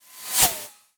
pgs/Assets/Audio/Guns_Weapons/Bullets/bullet_flyby_slow_03.wav at master
bullet_flyby_slow_03.wav